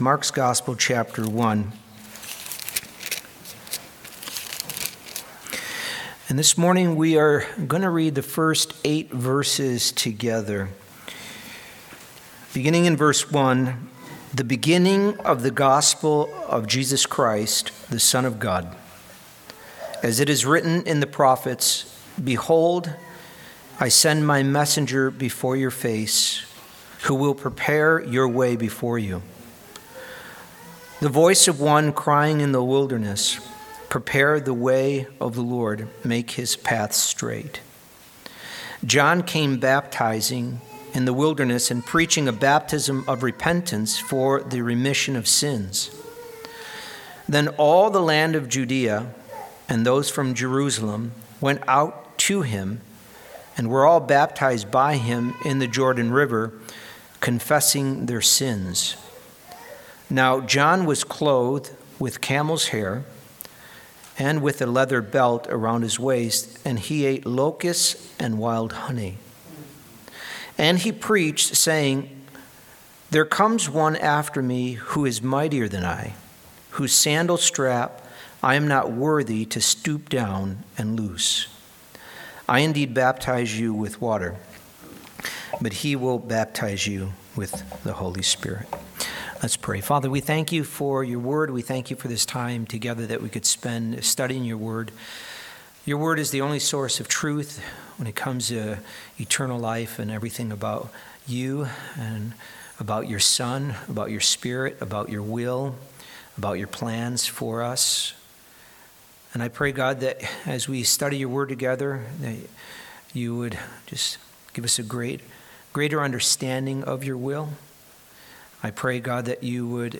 We turn to the Gospel of Mark today as we continue in our Sunday Sermon Series titled, “Messiah on the Move”.